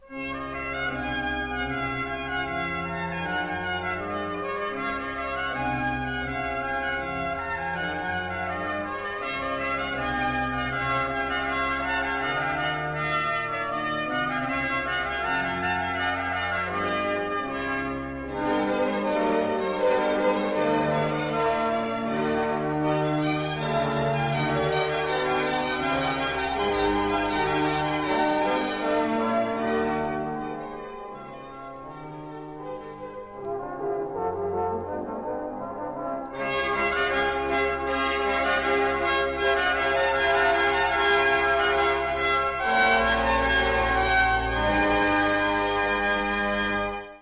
Vytvořil obsáhlé, převážně orchestrální dílo, nejčastěji s bohatým obsazením dechových nástrojů.
Po kompoziční stránce se inspiruje svými vídeňskými současníky, jeho skladby mají sice jednoduchou stavbu, přesto jsou velmi osobité díky zvuku žesťových nástrojů a melodické invencí, která má zjevné kořeny v moravské lidové hudbě.
Sonata Vespertina in C a 8 (Symfonický orchestr hl. města Prahy FOK a Komorní harmonie, řídí Libor Pešek; nahr.